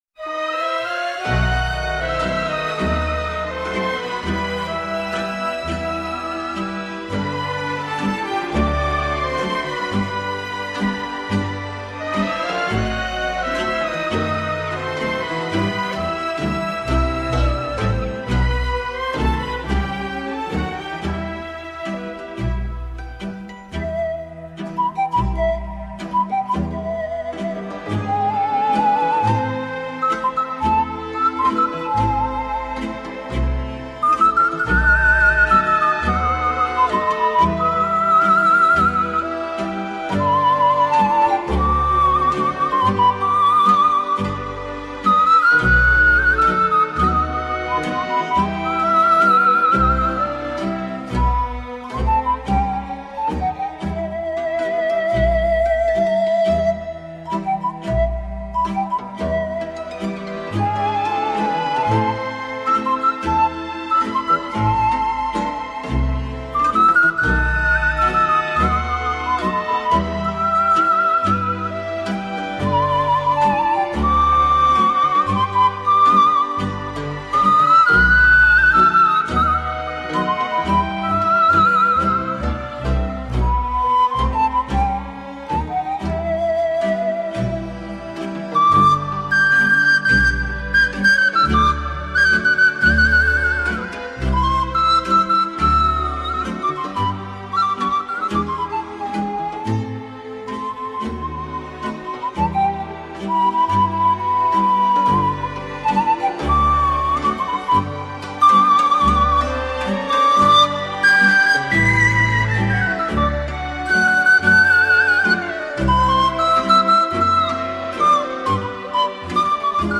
Данная хора называется свадебной (снятие фаты у невесты).
Данная хора -  народная. Соло - най!.